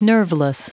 Prononciation du mot nerveless en anglais (fichier audio)
Prononciation du mot : nerveless